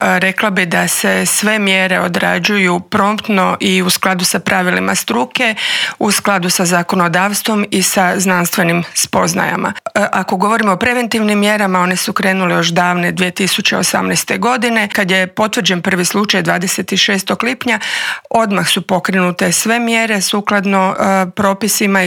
Socio-ekonomske posljedice su ogromne, a o tome kako izaći na kraj s ovom bolesti koja ne pogađa ljude u medicinskom, ali definitivno da u ekonomskom smislu, razgovarali smo u Intervjuu tjedna Media servisa s ravnateljicom Uprave za veterinarstvo i sigurnost hrane Tatjanom Karačić.